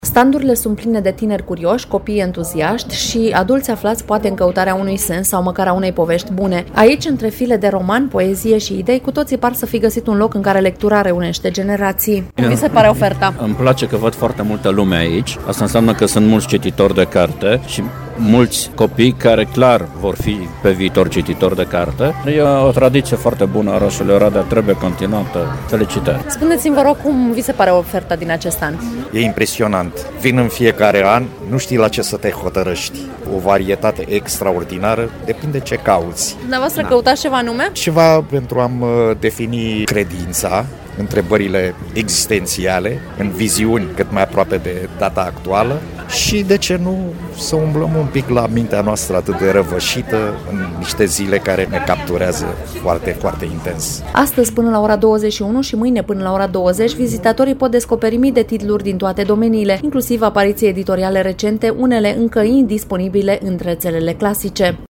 În Piaţa Unirii din Oradea continuă Târgul de Carte Gaudeamus Radio România.
Vizitatorii spun că oferta este atât de variată, încât cu greu se pot decide.